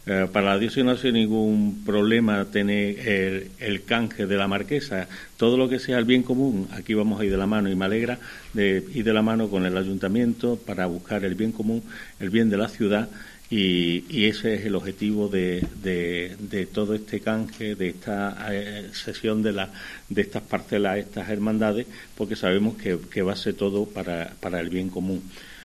Monseñor José Mazuelos explica los beneficios de esta permuta.